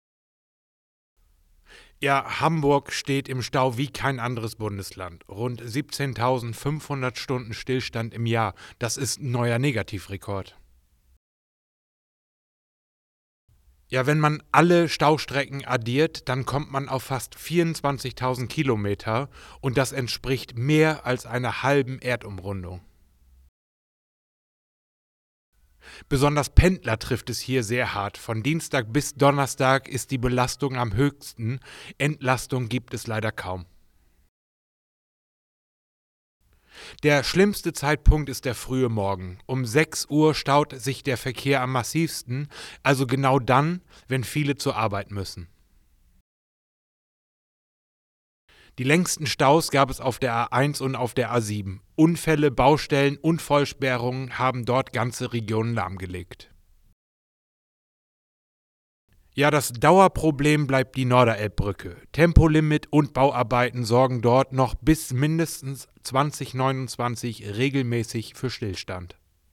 O-Ton-Paket